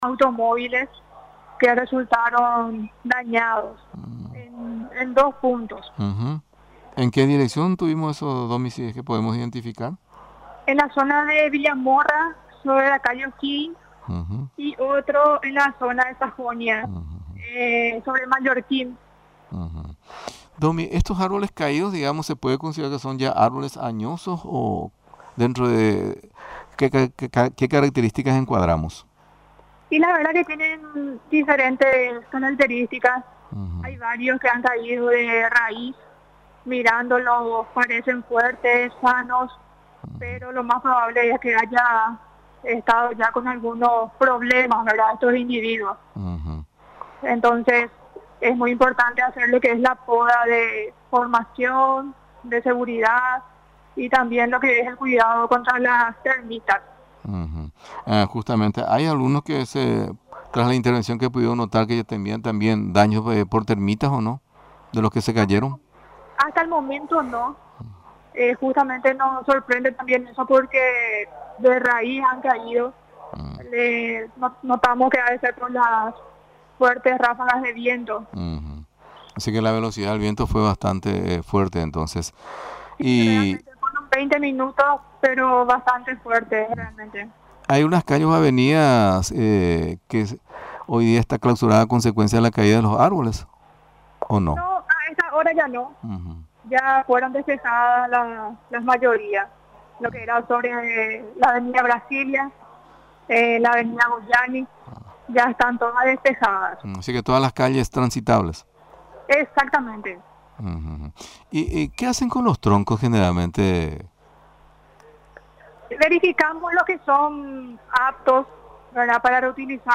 Seguidamente, durante la entrevista en Radio Nacional del Paraguay, recordó que estos trabajos tienen por finalidad garantizar la seguridad en las vías o calles de la capital.